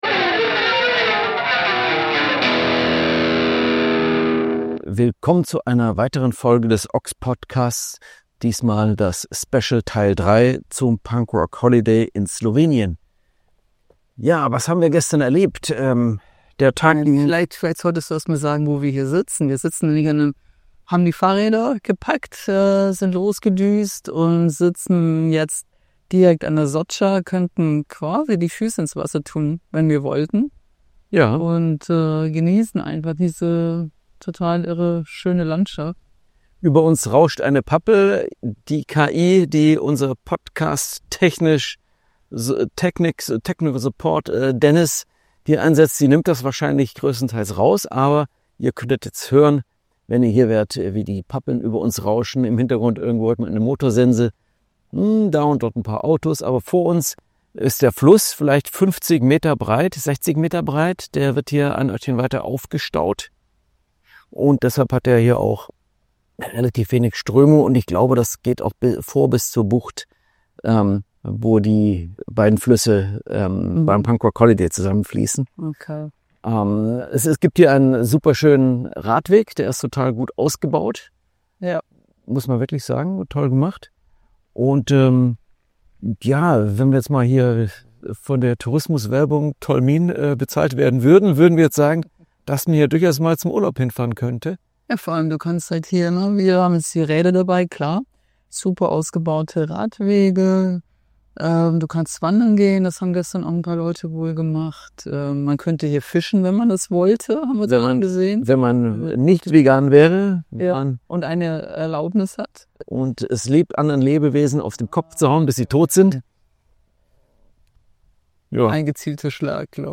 sind in Tolmin in Slowenien und berichten euch von vor Ort, wie sich so ein Punkrock-Urlaub anfühlt. Das ist der Podcast des Ox Fanzine.